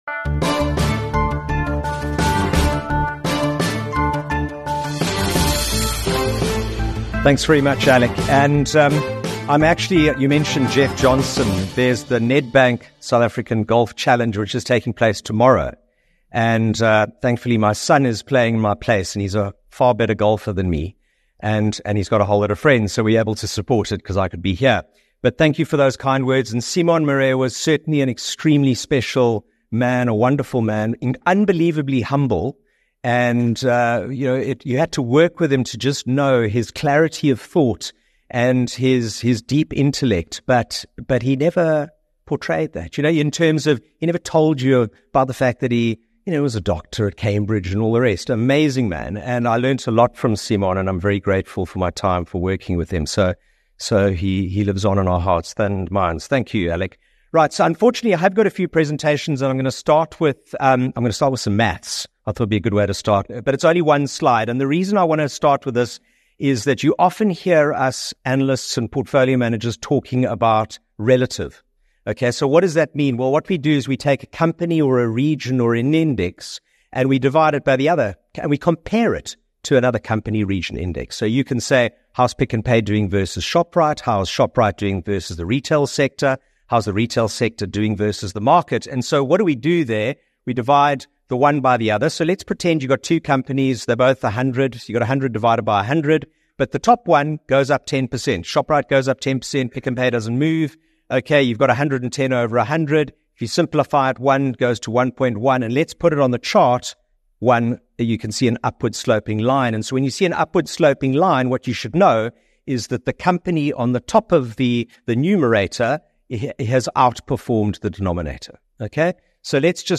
In a Q&A session, he urged caution, stressing that familiar names don’t guarantee safety, and savvy investors must look beyond the US to find true value in today's market.